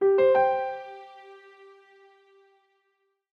ui_start.wav